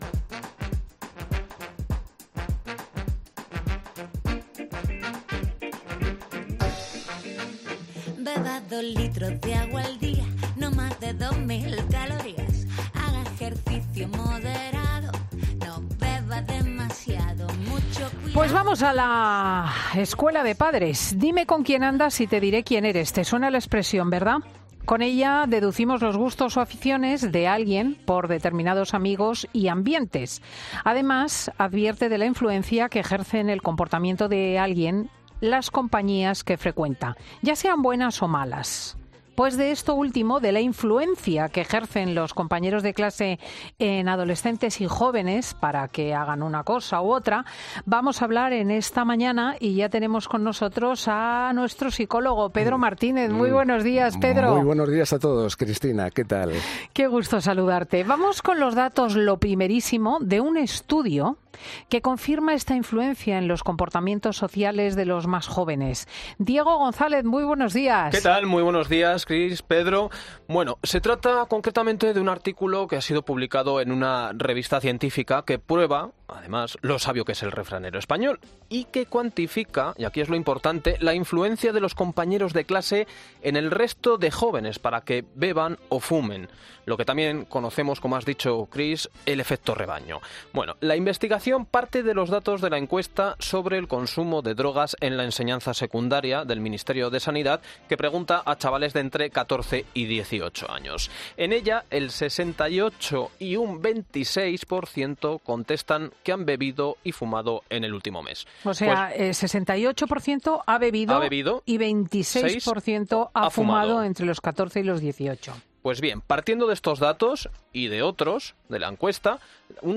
Redacción digital Madrid